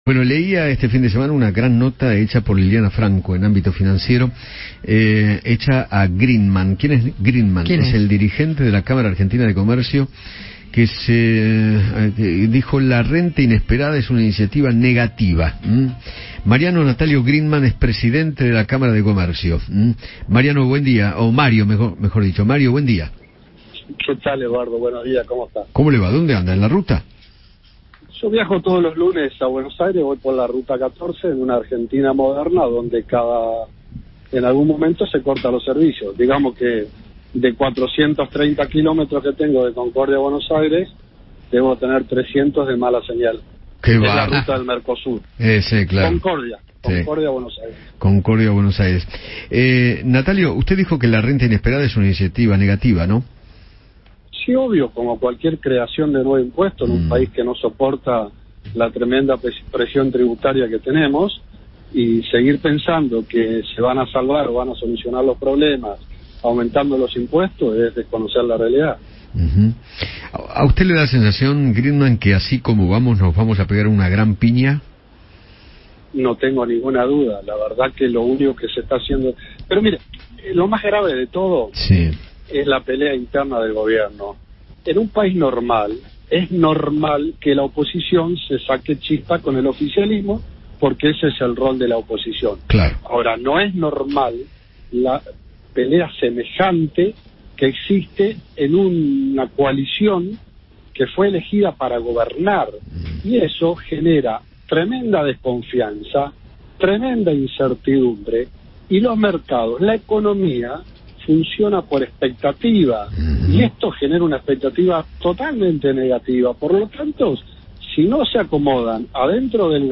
conversó con Eduardo Feinmann sobre el presente económico del país y se refirió al impuesto a la renta inesperada.